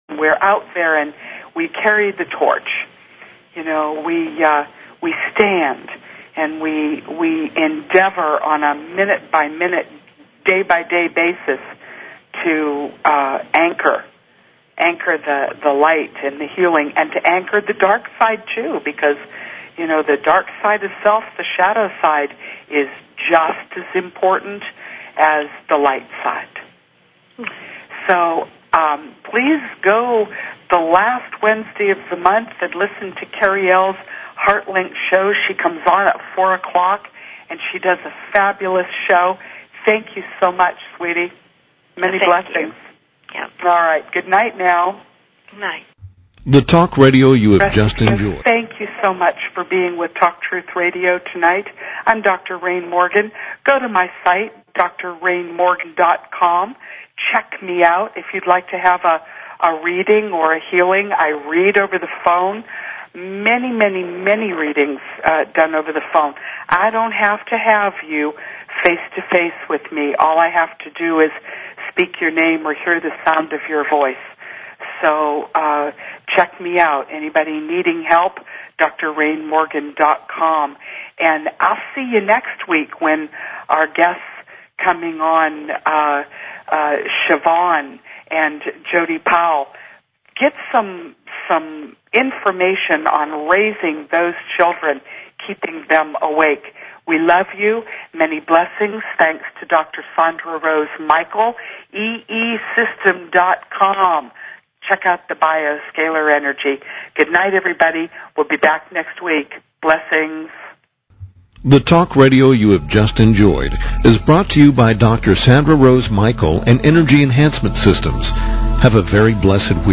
Talk Show